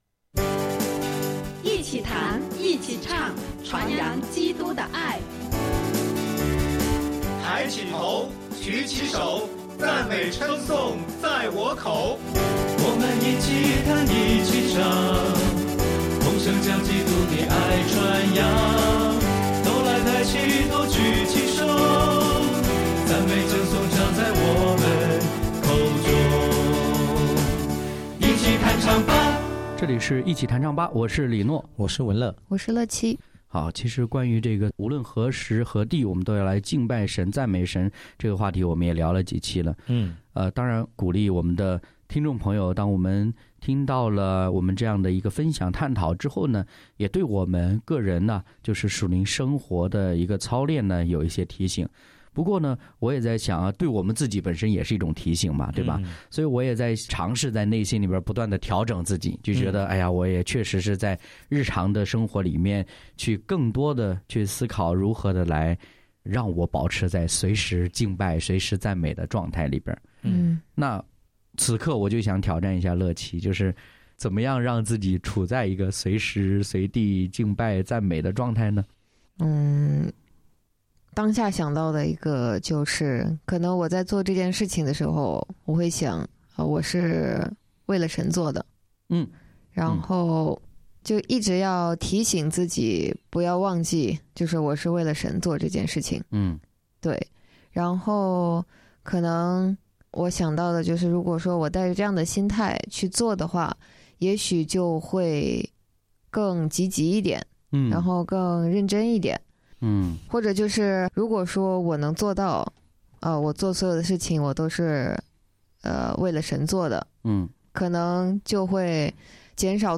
敬拜分享：无论何时，总要赞美（3）；诗歌：《让赞美飞扬》、《来欢呼来赞美》